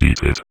VVE1 Vocoder Phrases 02.wav